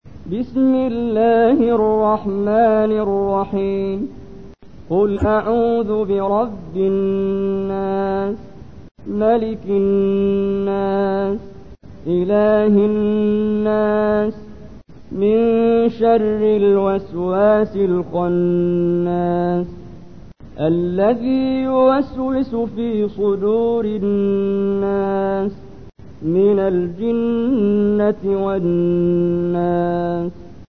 تحميل : 114. سورة الناس / القارئ محمد جبريل / القرآن الكريم / موقع يا حسين